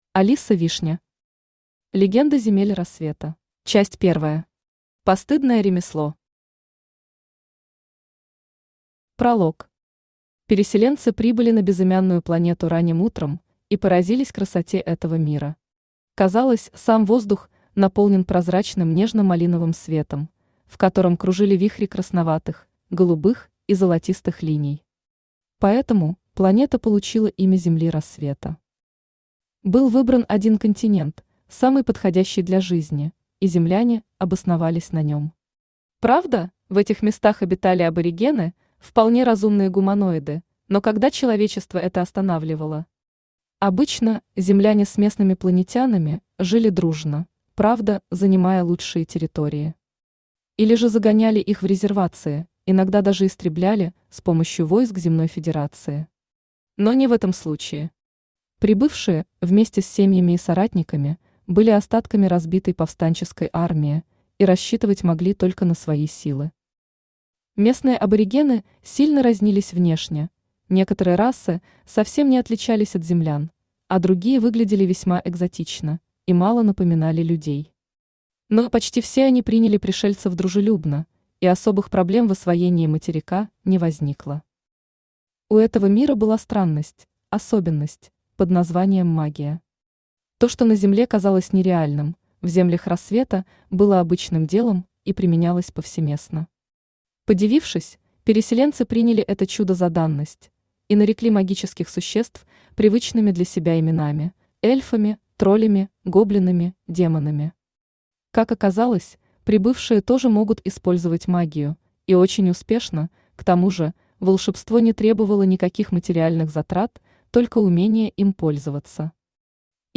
Аудиокнига Легенды Земель Рассвета | Библиотека аудиокниг
Aудиокнига Легенды Земель Рассвета Автор Алиса Вишня Читает аудиокнигу Авточтец ЛитРес.